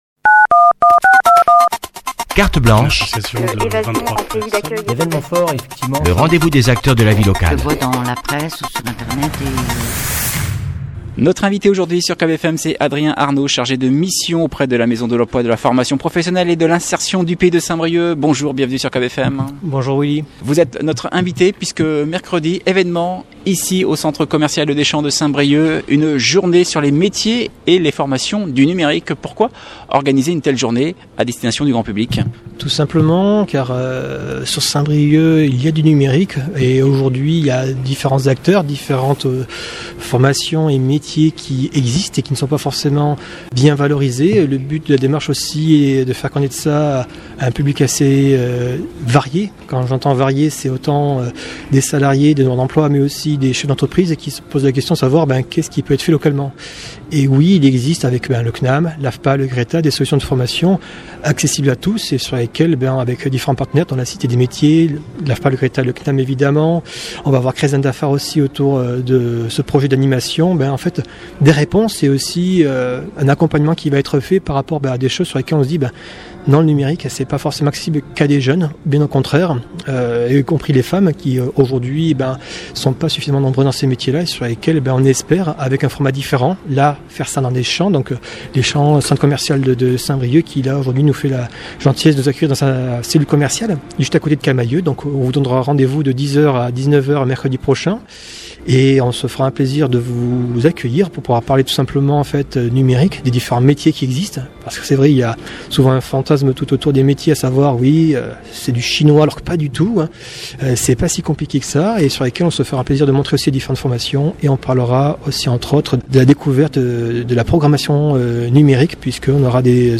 Cette entrée a été publiée dans Interviews.